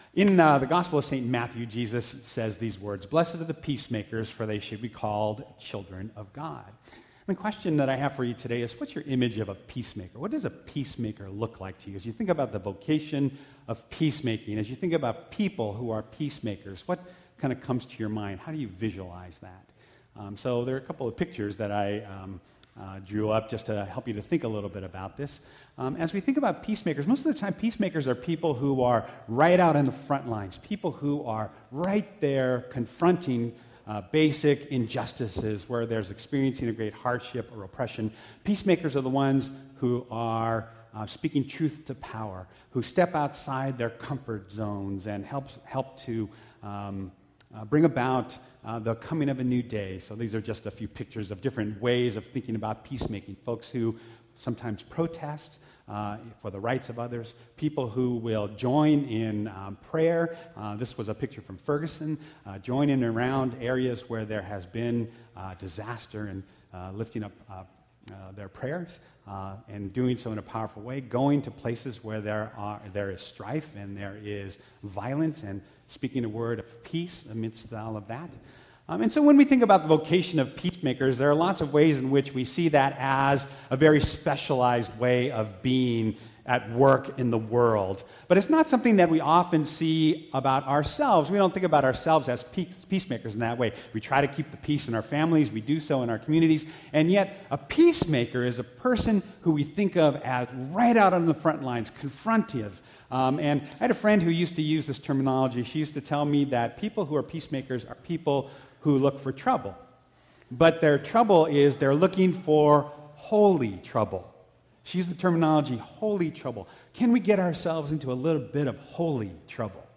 2016 Sermons – Blaine Memorial United Methodist Church